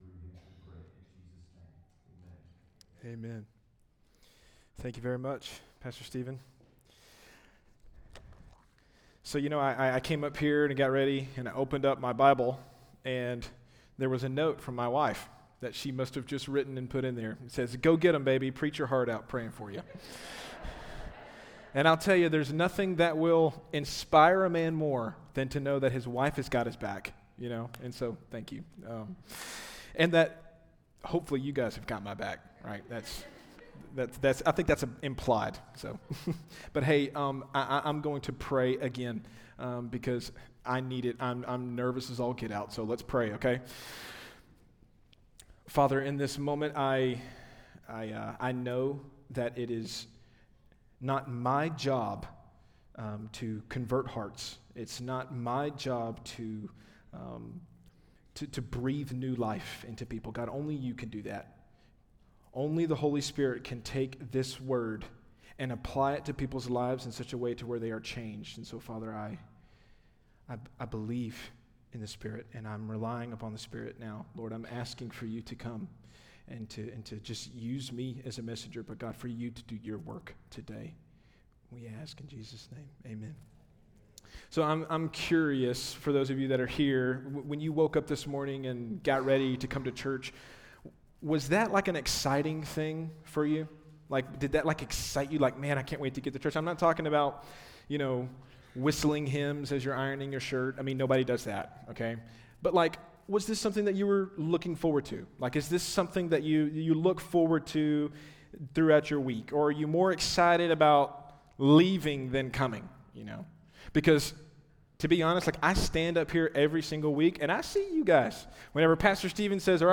Misc. Sermons